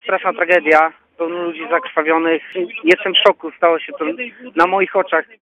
– mówi jeden ze świadków.